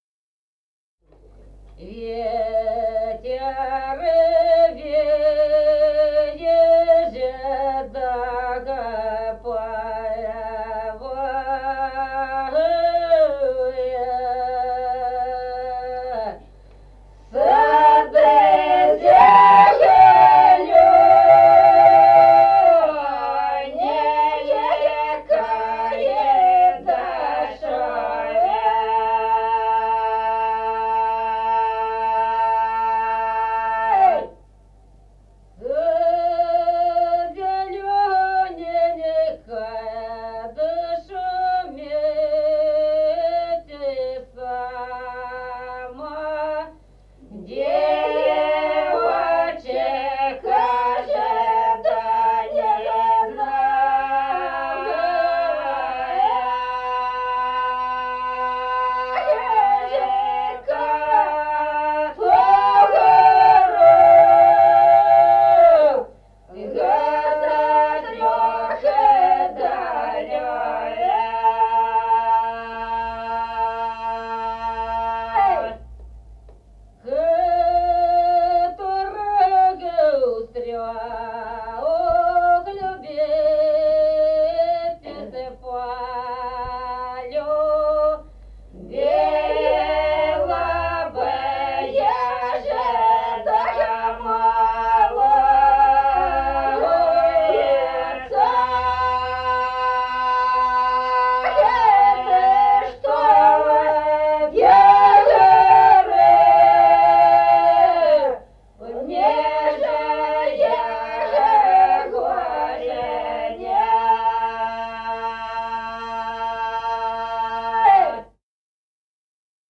Песни села Остроглядово в записях 1950-х годов